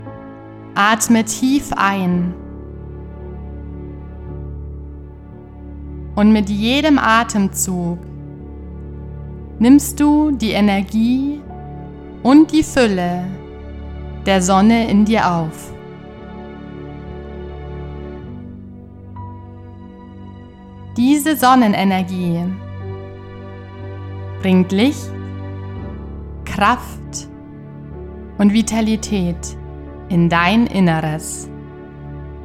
Die geführte Sommer Sonnenwende-Meditation lädt Dich ein, Dich mit den Elementen der Sonne, Wärme, Fülle und Dankbarkeit zu verbinden.